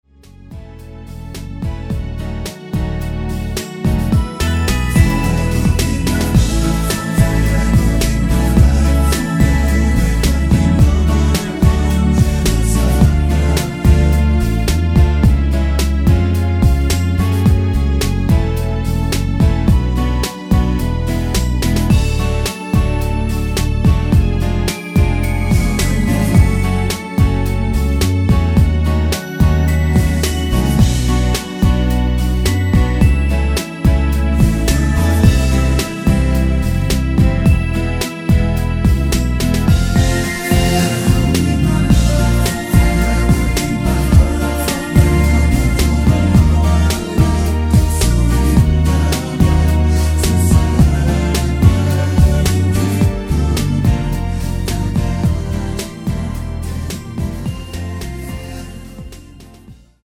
(-2) 내린코러스 포함된 MR 입니다.
앞부분30초, 뒷부분30초씩 편집해서 올려 드리고 있습니다.
중간에 음이 끈어지고 다시 나오는 이유는